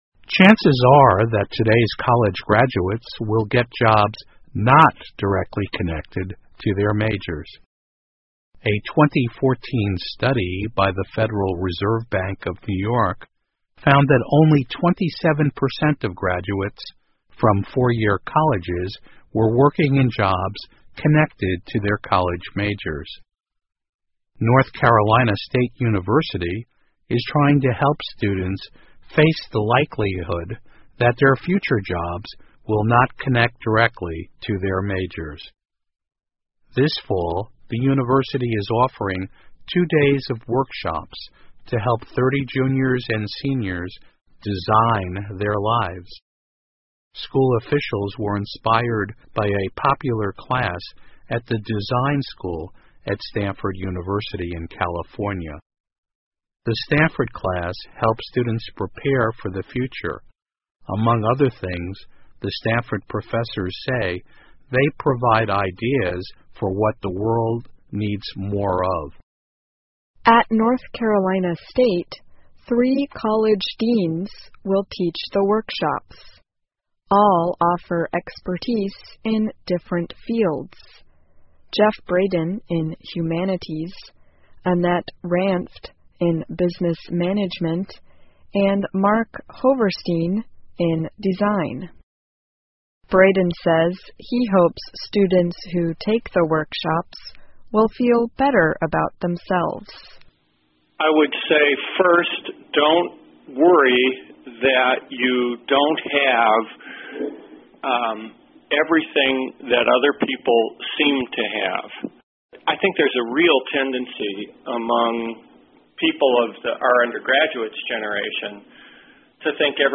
VOA慢速英语2017--帮助学生适应就业市场 听力文件下载—在线英语听力室